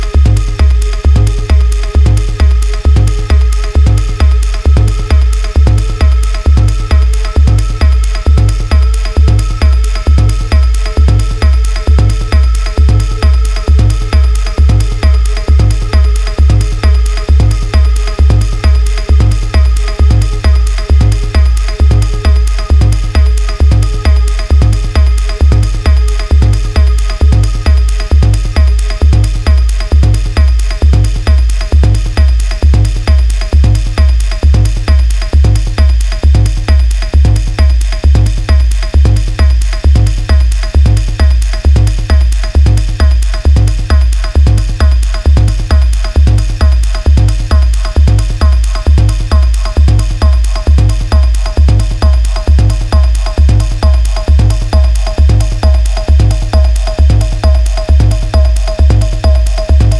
Top-notch drone techno on this nice new label.